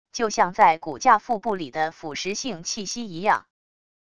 就像在骨架腹部里的腐蚀性气息一样wav音频